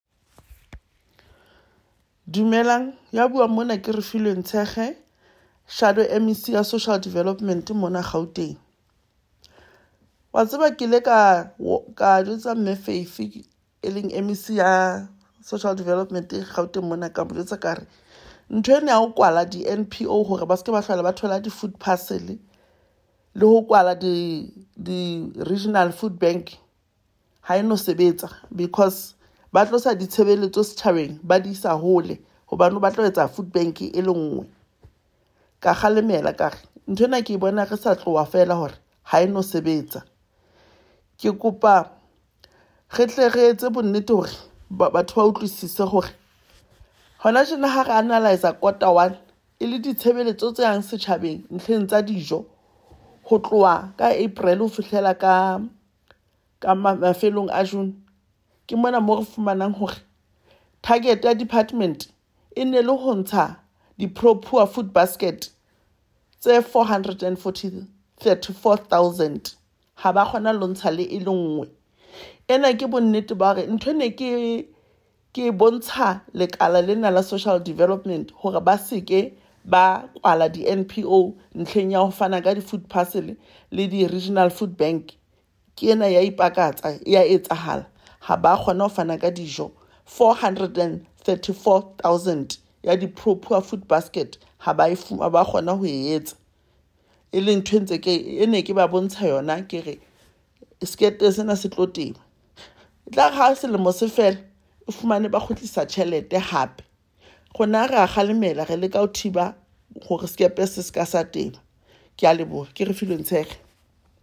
Sesotho soundbites by Refiloe Nt’sekhe MPL.